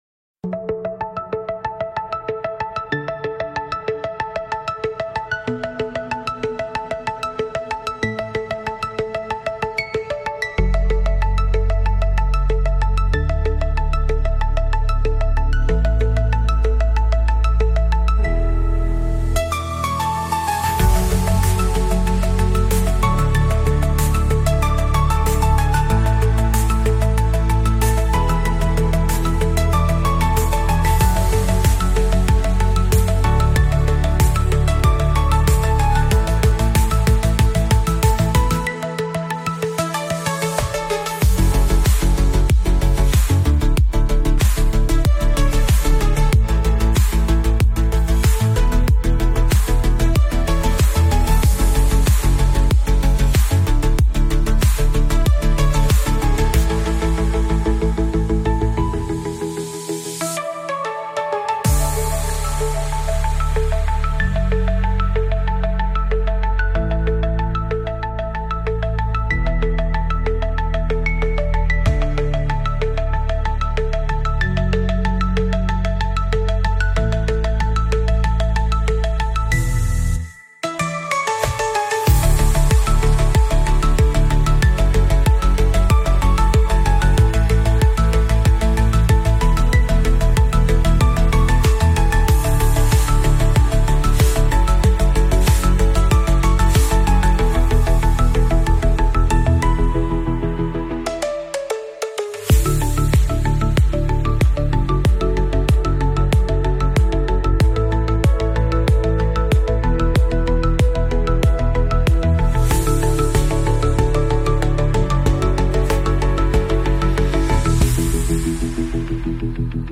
ListenWatch on YouTube  Ethereal pop, Chill pop, Synthpop